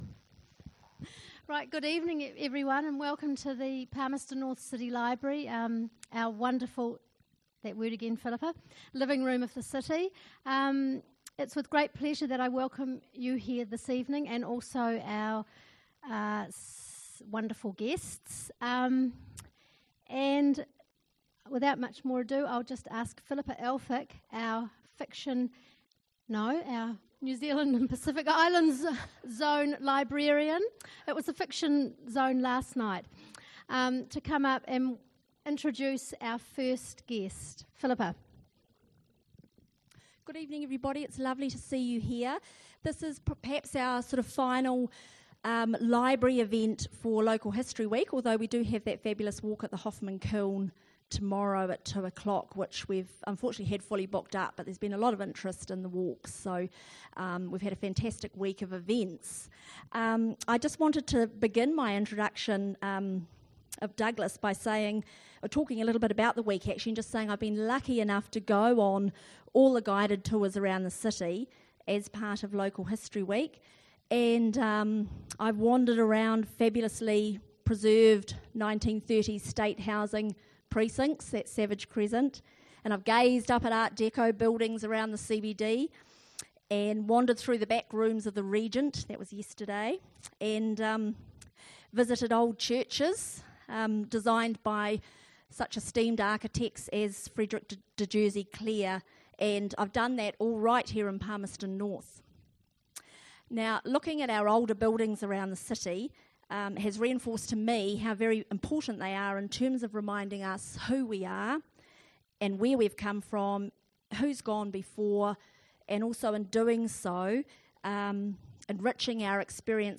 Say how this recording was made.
event recordings